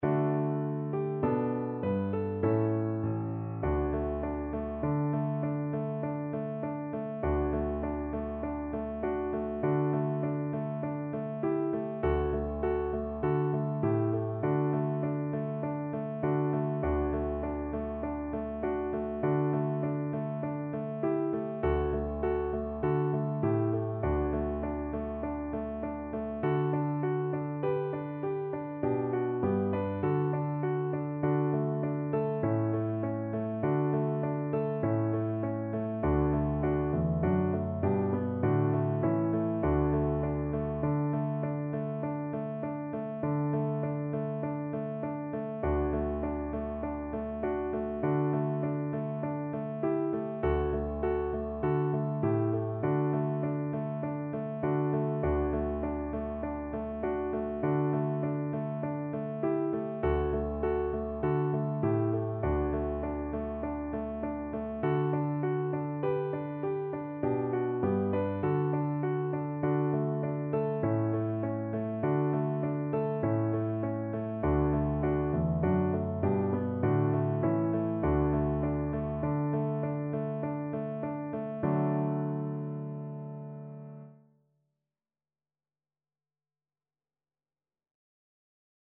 D major (Sounding Pitch) (View more D major Music for Voice )
Moderato
Traditional (View more Traditional Voice Music)